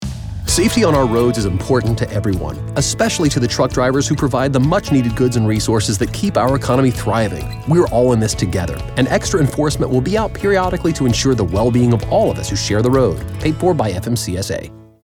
Audio Spot
FMCSA Safe Drive Radio General.mp3